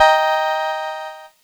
Cheese Chord 09-D#3.wav